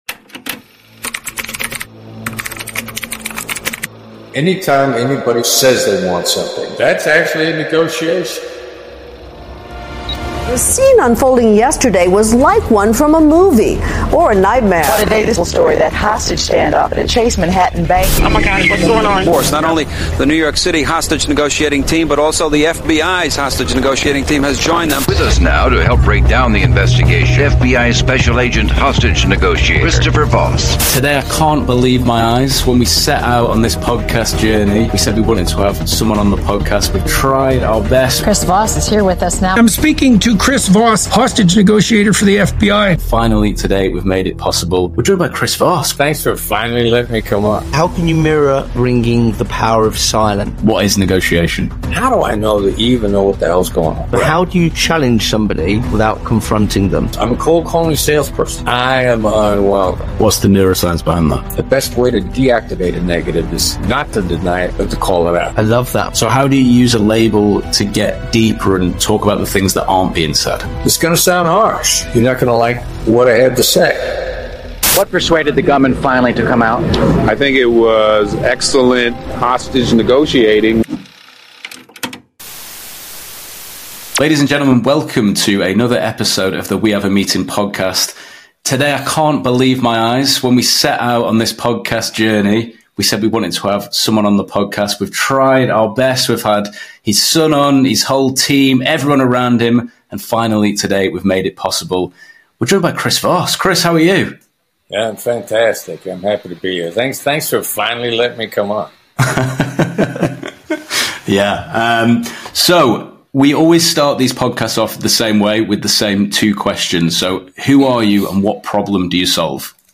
Join us for an exclusive conversation with Chris Voss, renowned former FBI hostage negotiator and the author of the international bestseller 'Never Split the Difference'. Chris is one of the world's leading experts on negotiation, and in this episode, he shares his invaluable insights that can transform your approach to communication and collaboration.